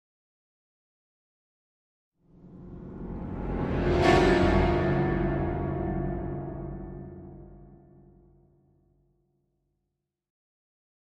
Piano Reversed Danger Chord Type A - Suspense